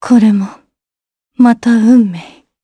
Isolet-Vox_Dead_jp.wav